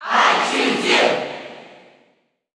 Crowd cheers (SSBU) You cannot overwrite this file.
Pokémon_Trainer_Cheer_English_NTSC_SSBU.ogg